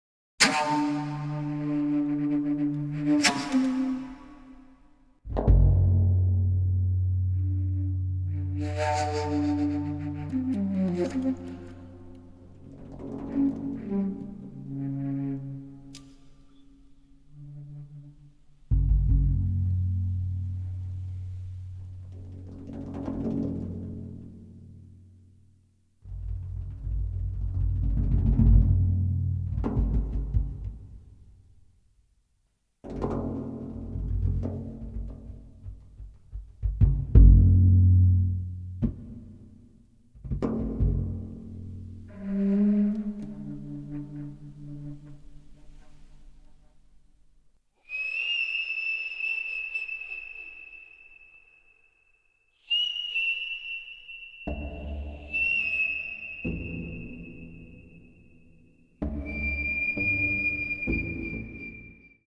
Sound Track
SYNTHESIZER
PERCUSSIONS